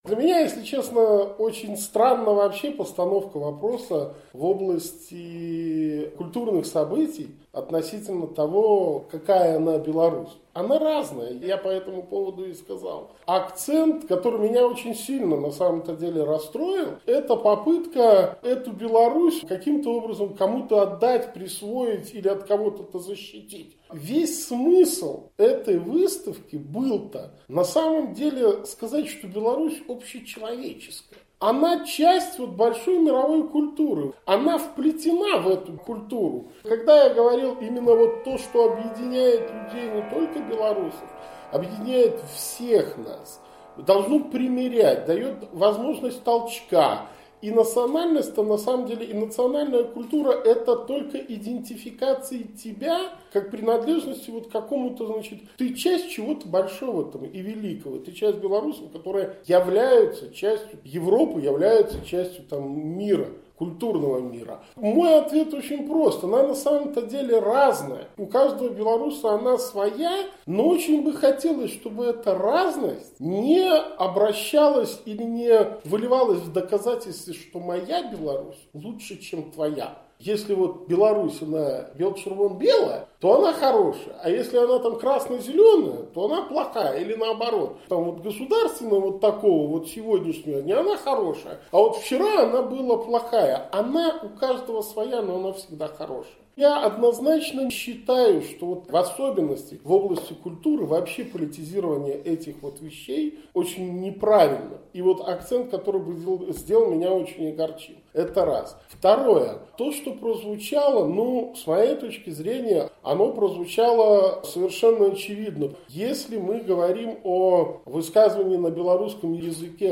Пра ролю бізнэсу ў вяртаньні нацыянальных артэфактаў з старшынём праўленьня «Белгазпрамбанку» Віктарам Бабарыкам пагутарыў